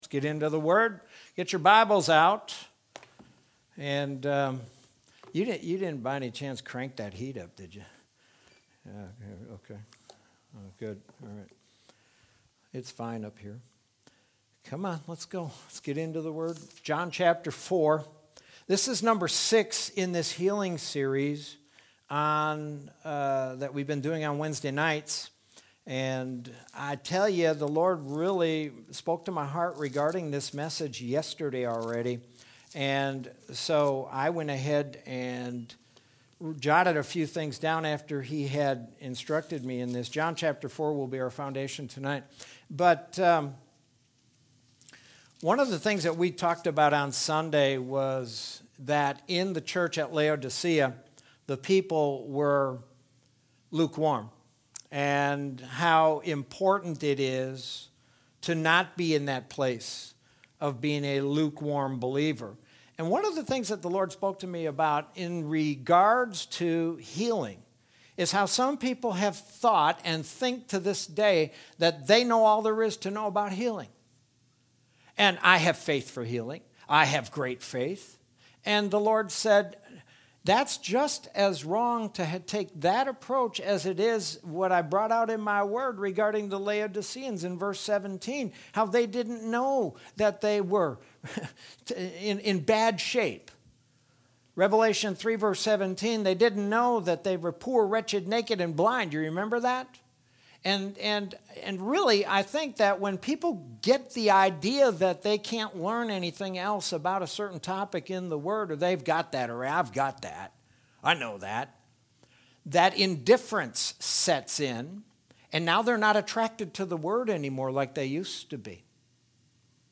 Sermon from Wednesday, February 17th, 2021.